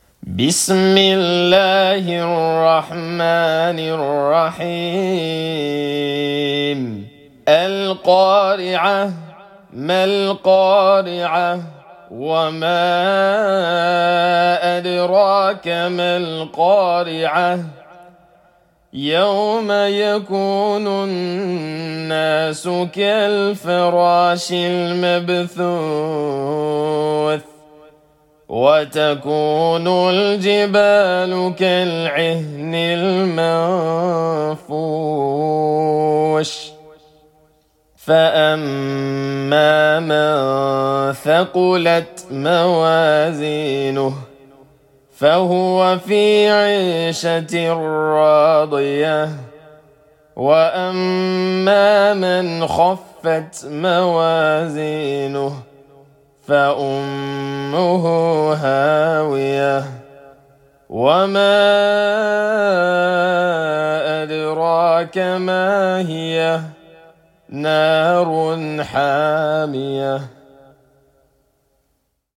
File:Chapter 101, Al-Qaria (Murattal) - Recitation of the Holy Qur'an.mp3 - Wikipedia
Chapter_101,_Al-Qaria_(Murattal)_-_Recitation_of_the_Holy_Qur'an.mp3